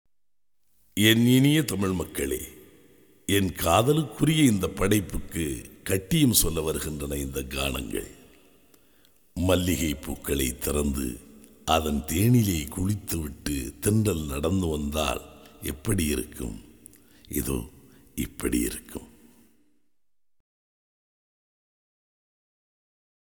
Recorded Panchathan Record Inn